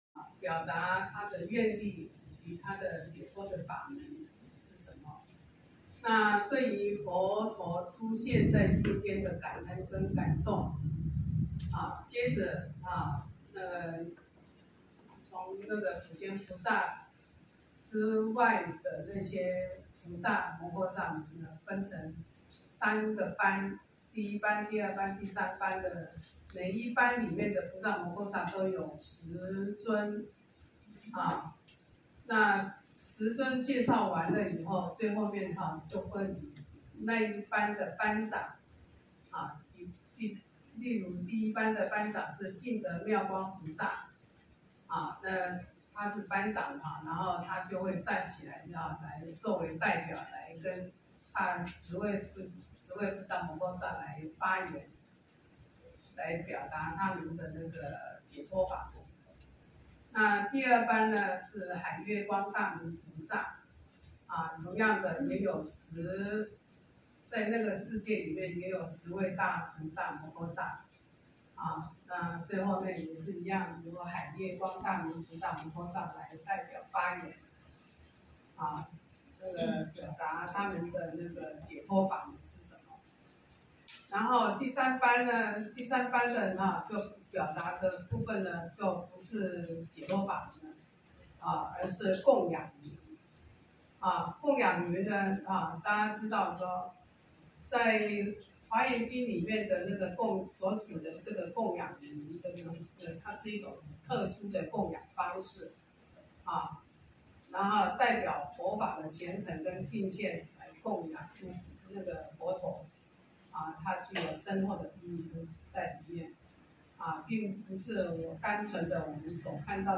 週六共修---讀誦華嚴經第5卷